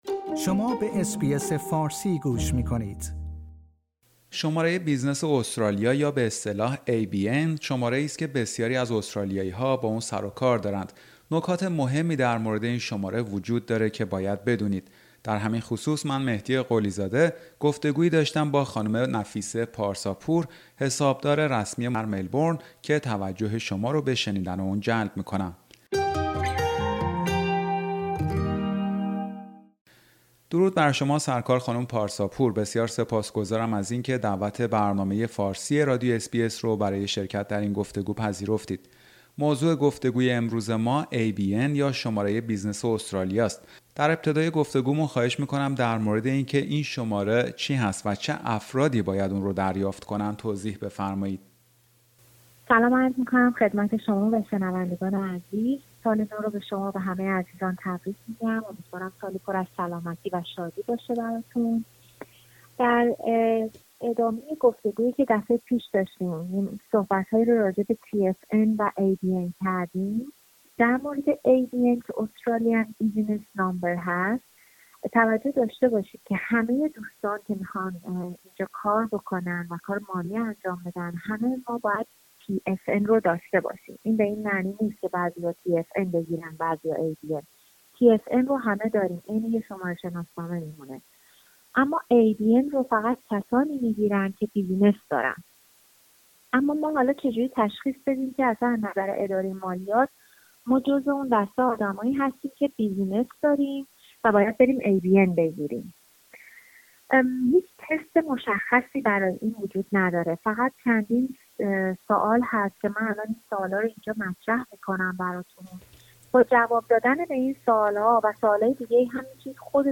گفتگویی